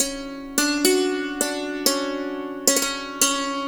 SANTOOR1  -L.wav